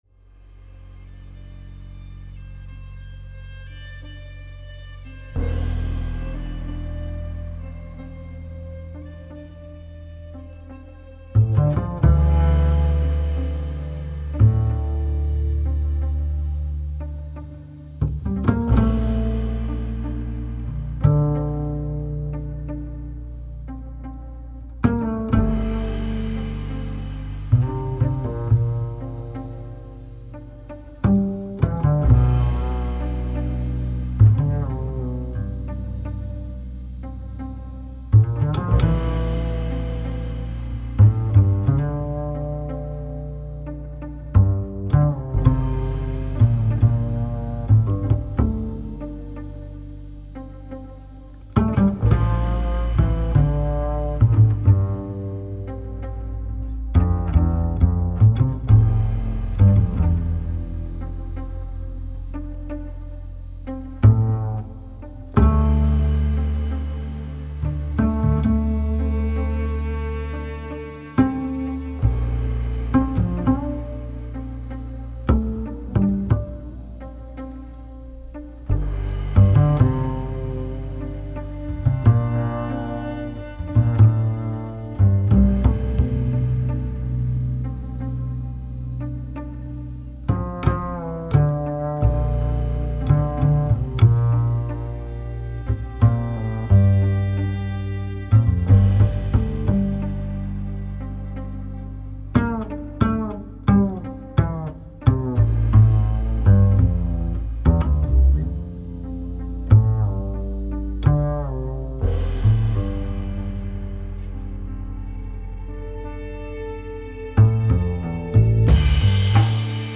BASS SOLO